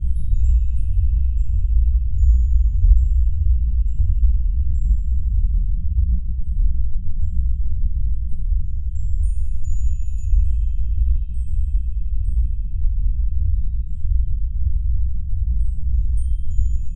new portal energy.wav